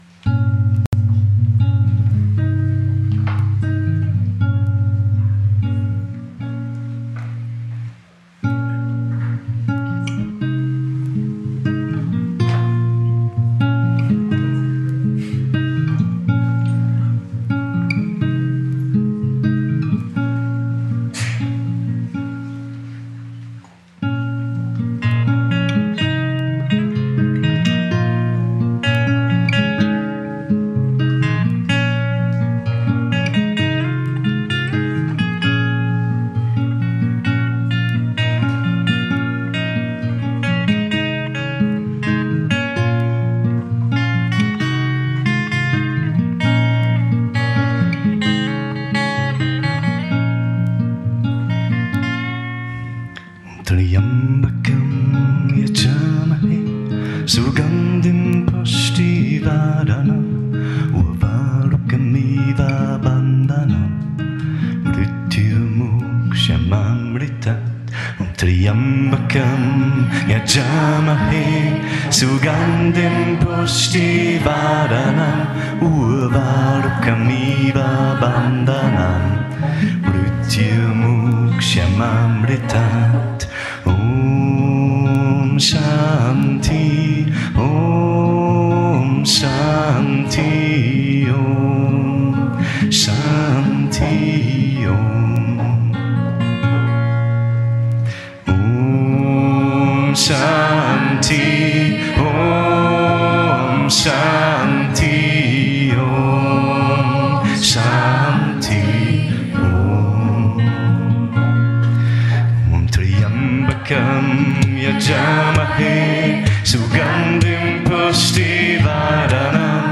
Sanskrit Chants